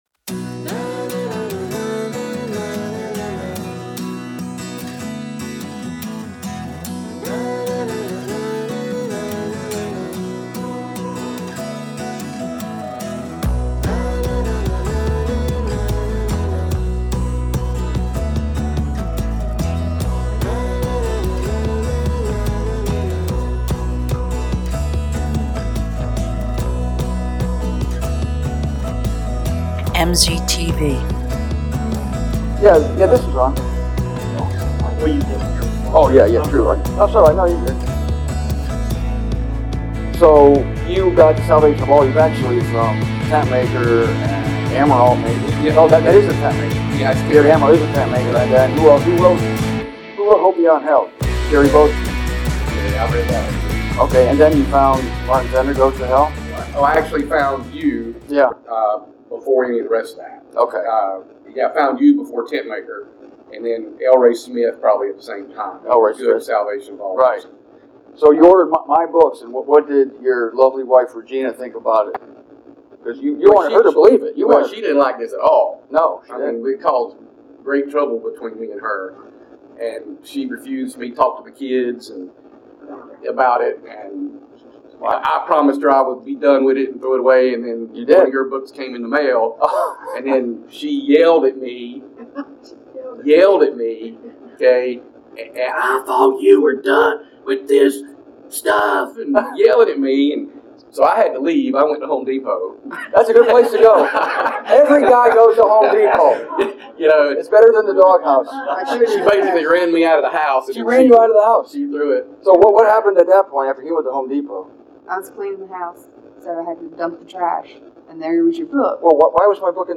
Richmond Conference 6